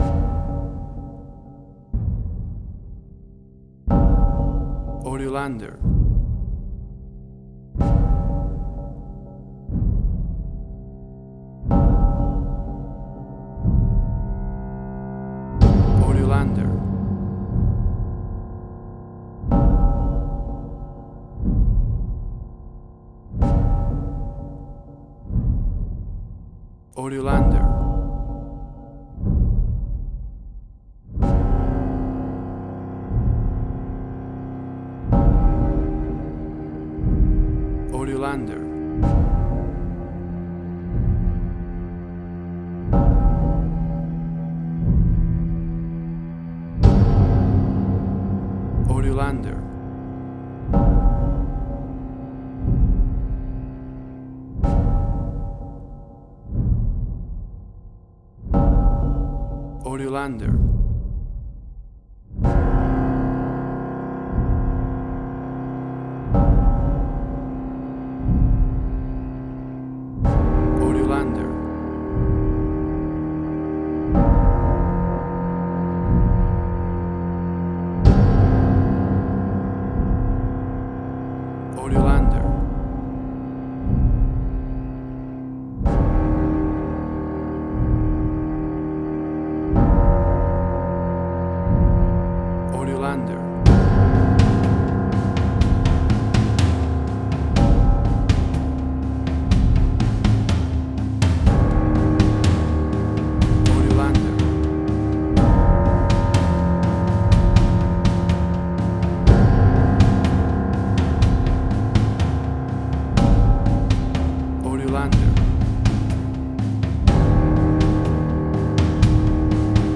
Spaghetti Western
Tempo (BPM): 61